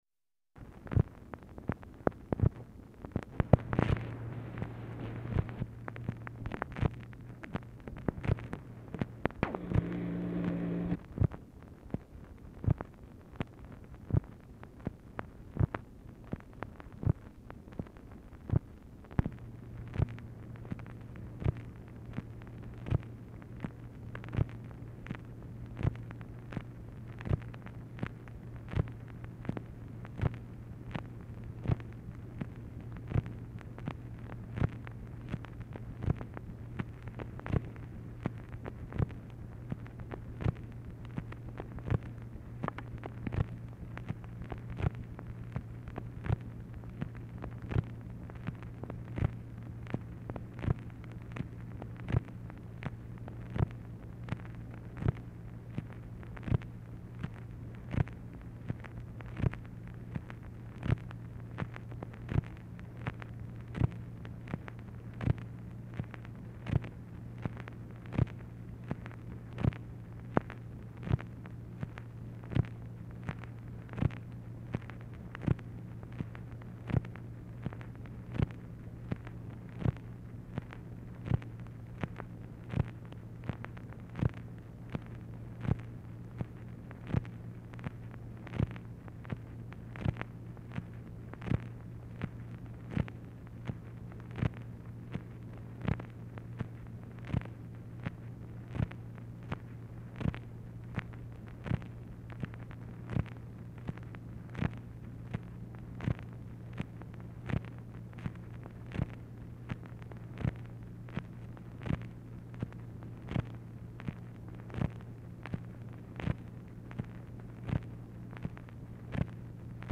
Telephone conversation # 63, sound recording, MACHINE NOISE, 11/26/1963, time unknown | Discover LBJ
Format Dictation belt